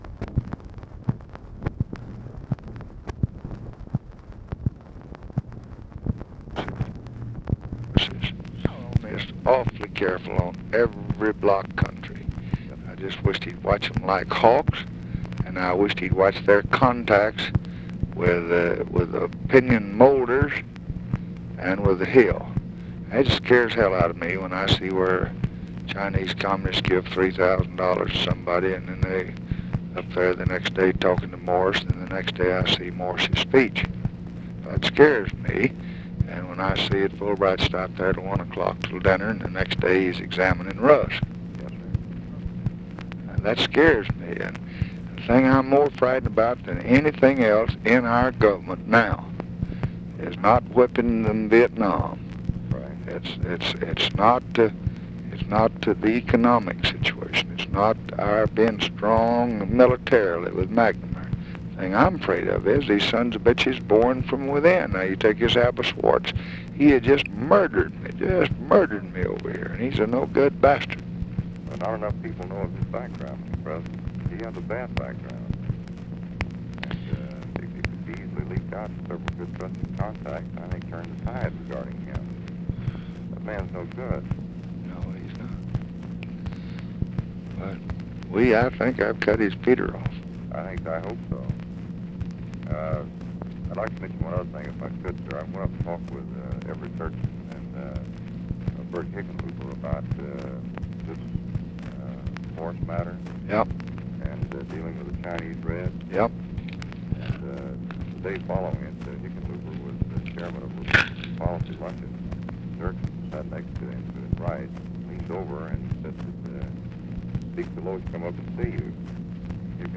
Conversation with CARTHA DELOACH, March 15, 1966
Secret White House Tapes